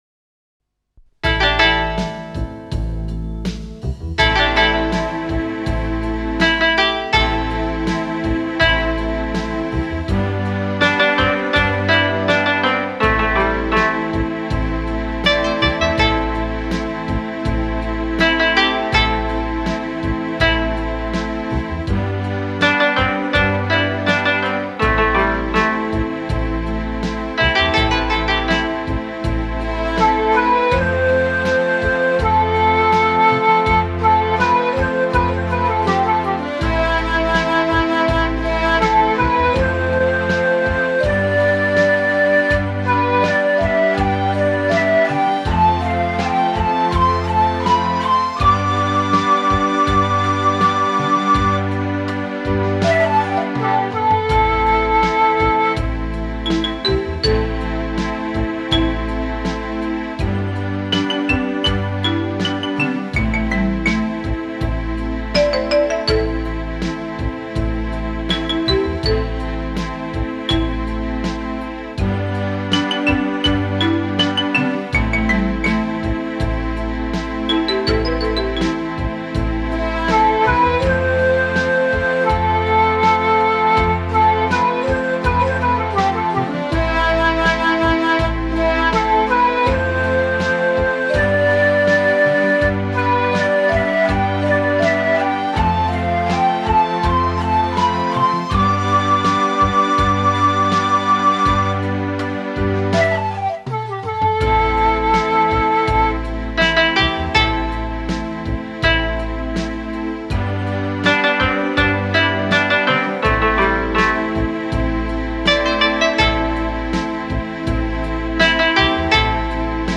Жанр: Ethnic / China Год выпуска диска